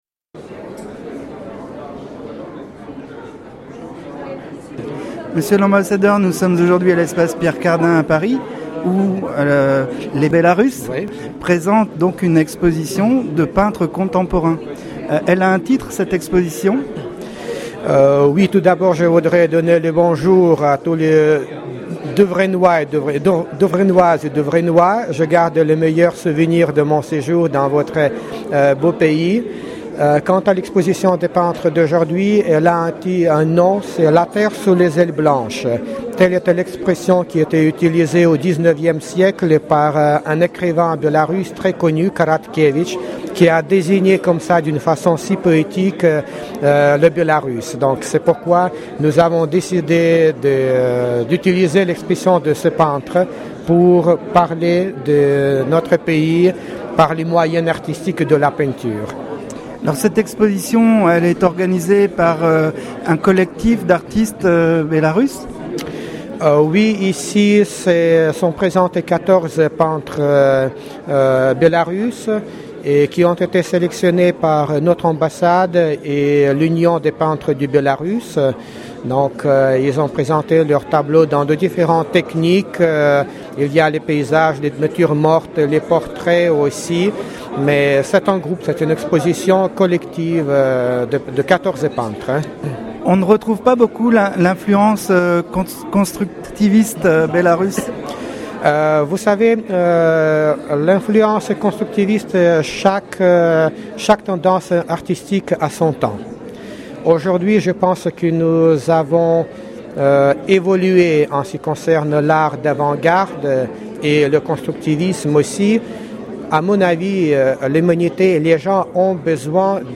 itv_ambassadeur_belarus.mp3